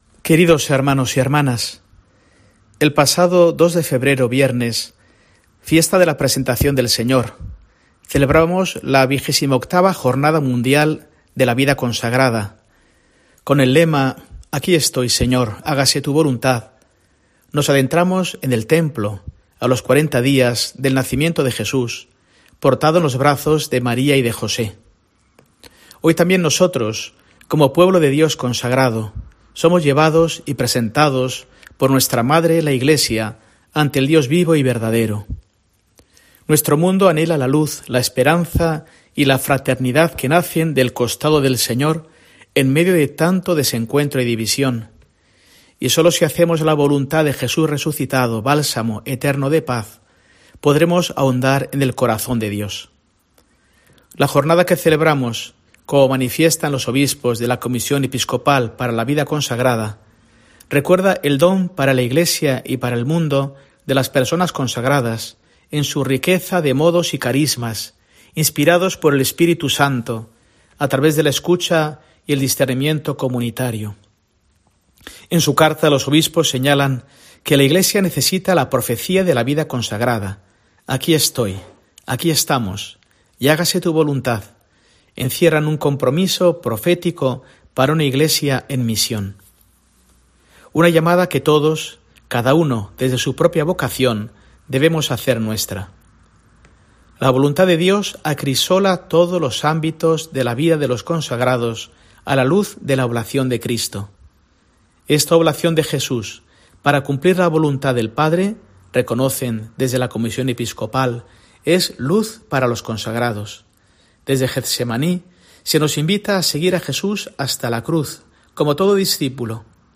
Mensaje del arzobispo de Burgos para el domingo, 4 de febrero de 2024